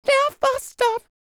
DD FALSET086.wav